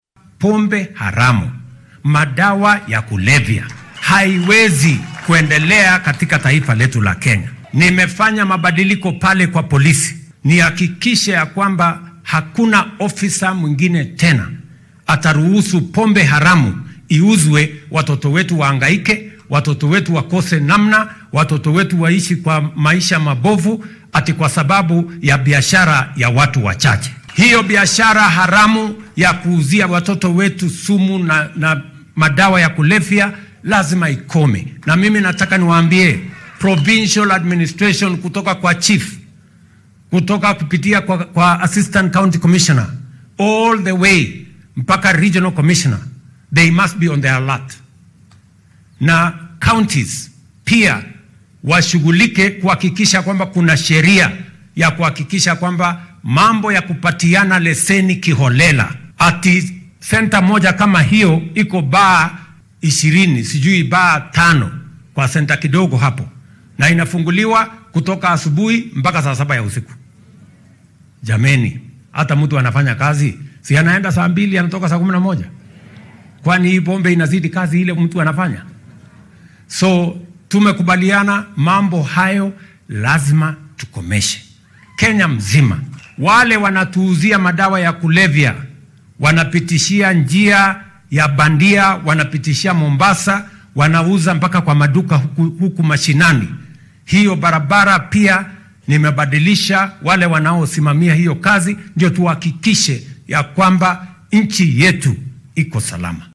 Madaxweynaha dalka William Ruto oo arrimahan ka hadlay xilli uu maanta munaasabad kaniiseed uga qayb galay Ndaragwa oo ka tirsan ismaamulka Nyandarua ayaa yiri.